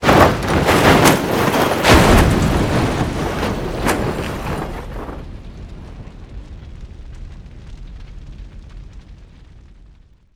vehicleExplode.wav